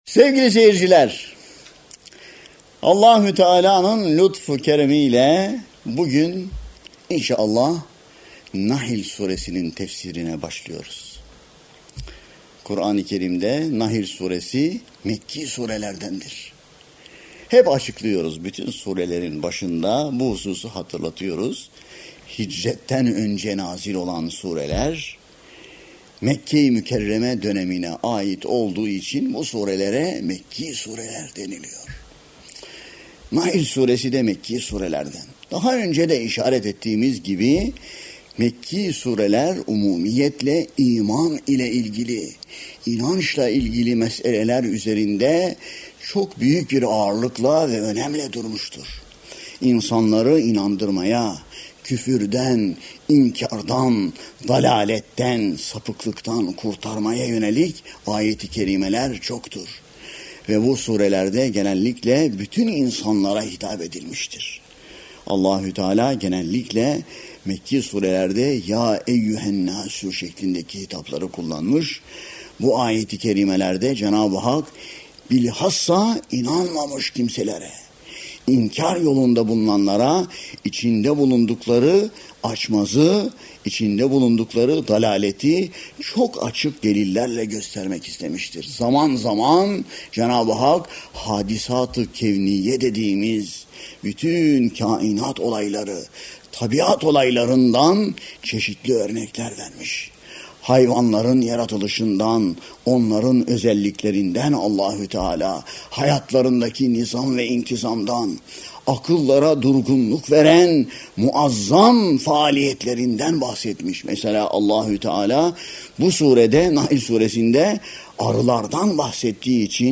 Tefsir